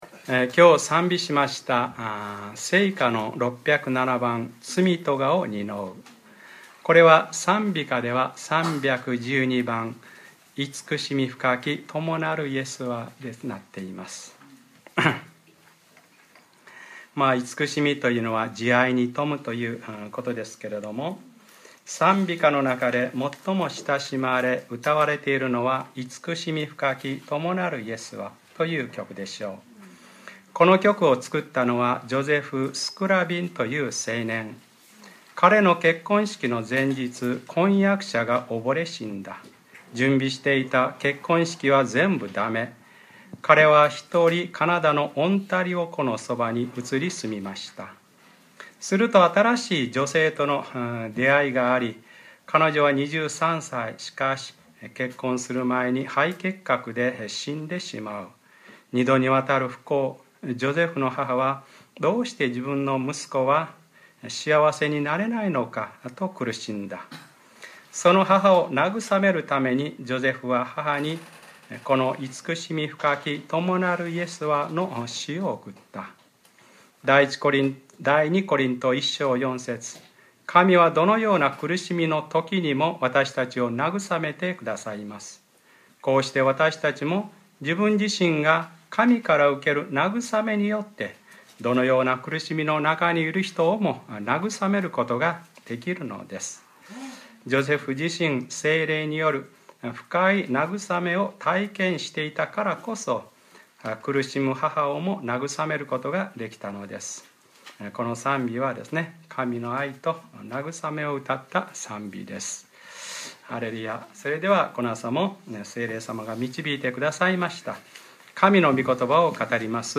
2013年6月02日(日）礼拝説教 『そのひとり子をお与えになったほどに』